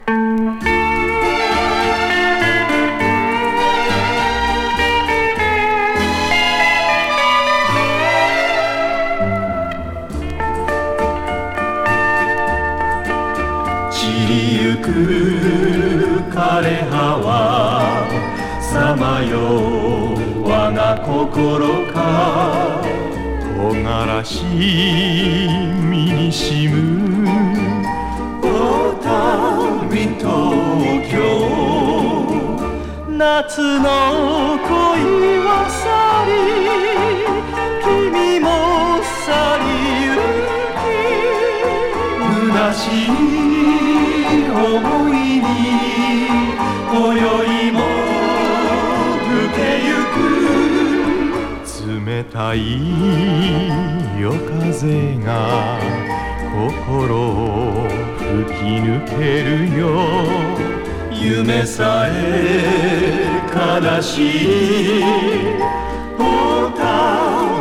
イントロからトロケます。